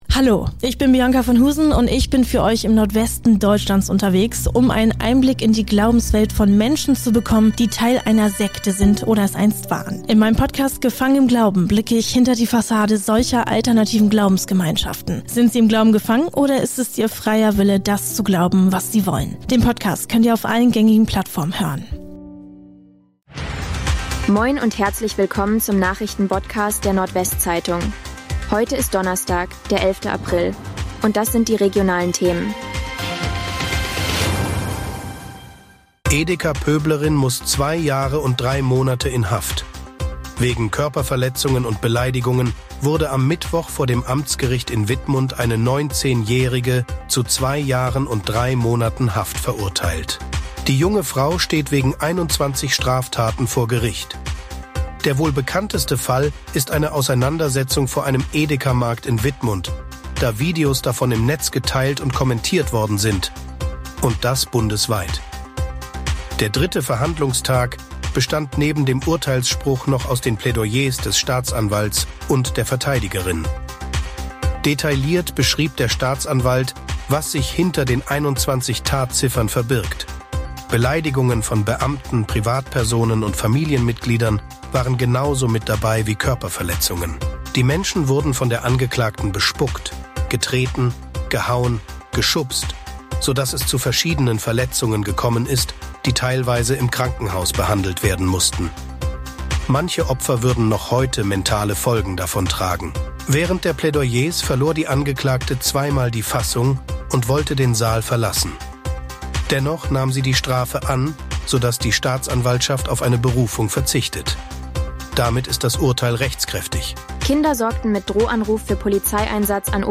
NWZ Nachrichten Botcast – der tägliche News-Podcast aus dem Norden
Nachrichten